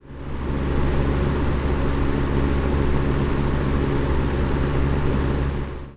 PIANETA GRATIS - Audio Suonerie - Casa (Domestici) - Pagina 21
audio_suonerie_rumori_casa_01.wav